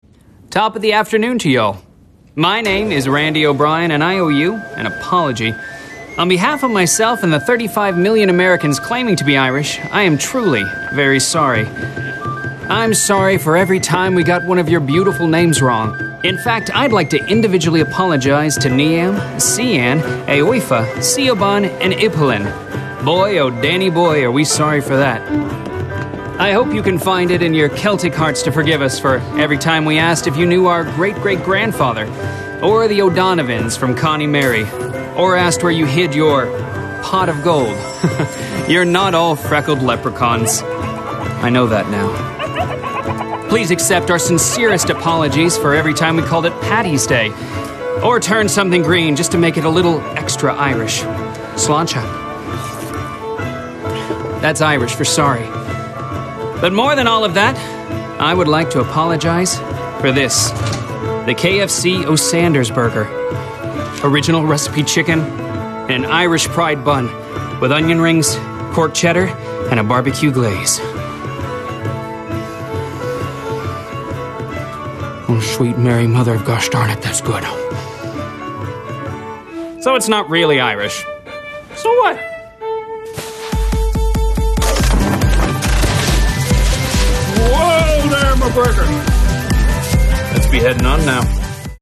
Commercial, Bright, Corporate, Light